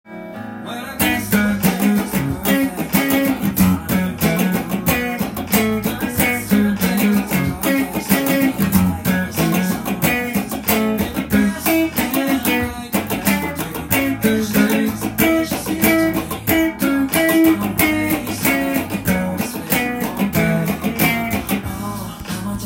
音源にあわせて譜面通り弾いてみました
音源に合わせて弾く時は周波数を４３６になりますのでチューニングの時に
keyがEになるのでポジションが微妙に弾きにくい感じです。
左手でミュートしながらコードストロークのように